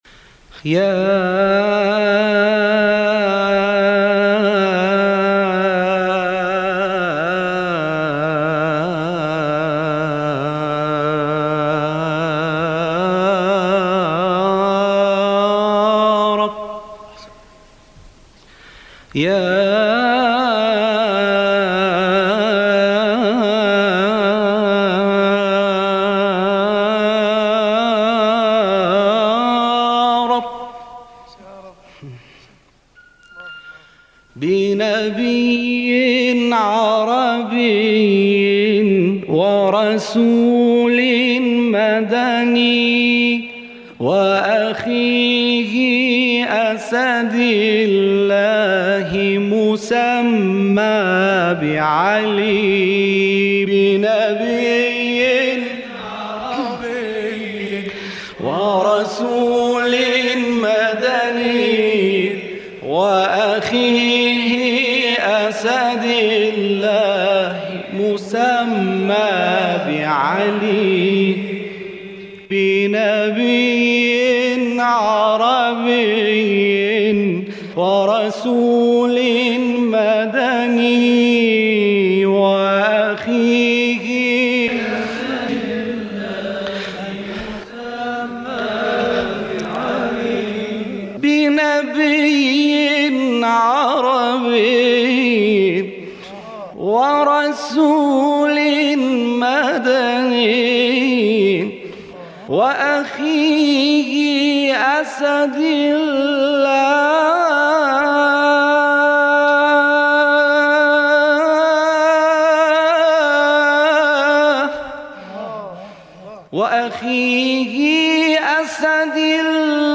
ابتهال‌خوانی
در یک محفل قرآنی که در سال 1387 برگزار شده بود، تواشیح
در انتهای این تواشیح، صدای تشویق‌های